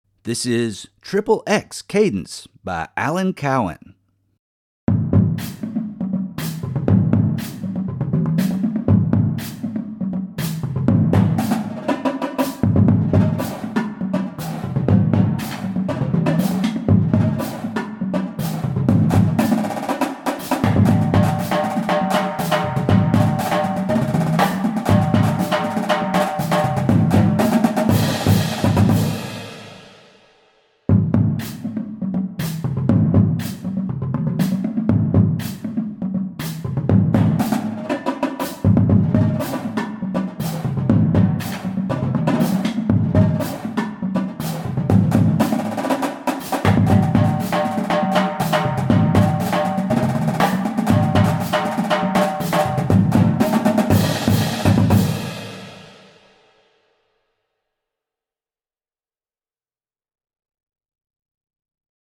Voicing: Cadence